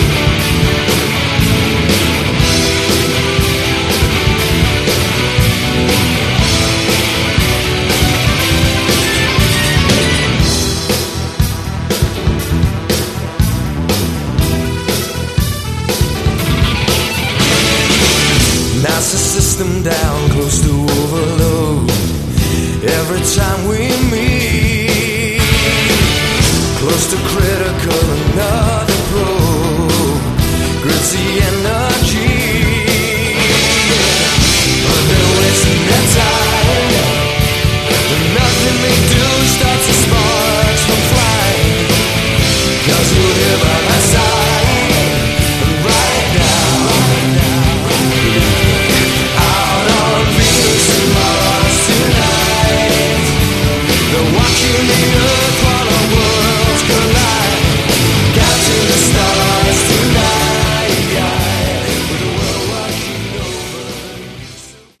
Category: AOR / Melodic Hard Rock
Vocals
Guitars
Bass
Keyboards
Drums
Bonus Studio Track